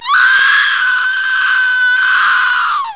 SCREAM.
scream.wav